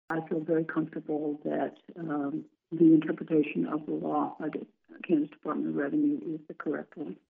She tells KMAN that she and Kobach differ on their interpretations of the new Women’s Bill of Rights law enacted July 1.